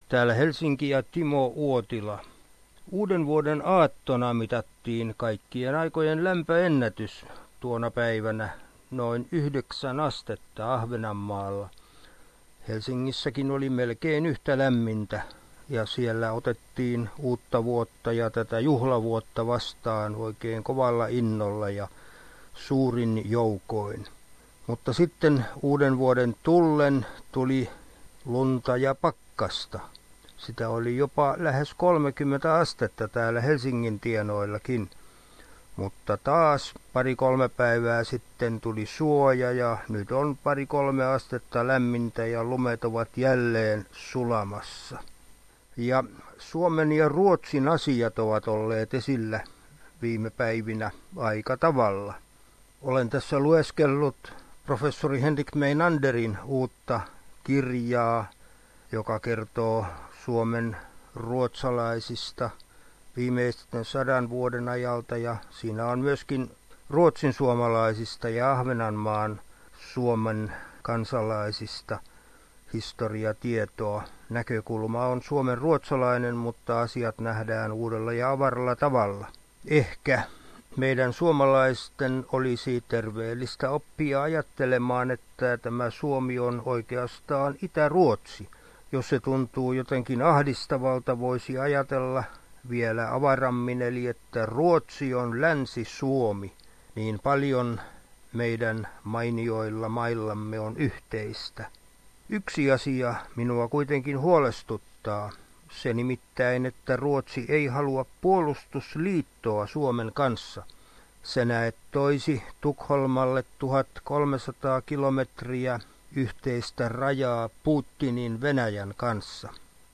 ajankohtaisraportti Suomesta